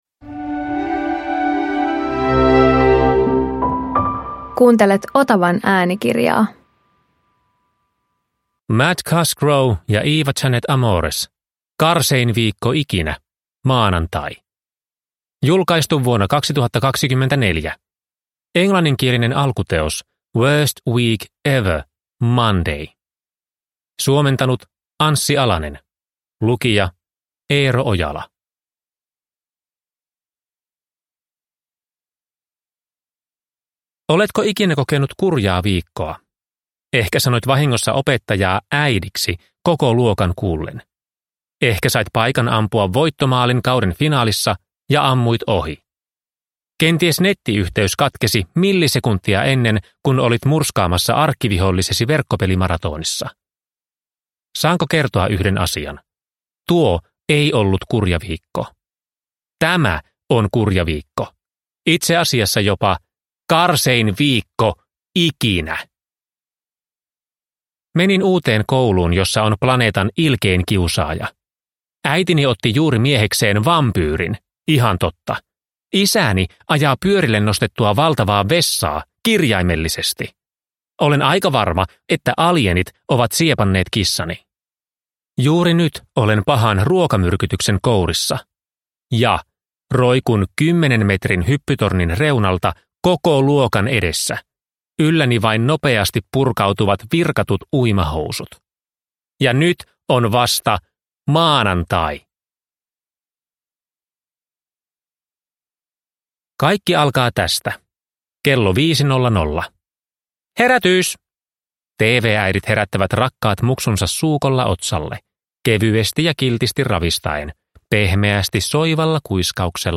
Karsein viikko ikinä: maanantai – Ljudbok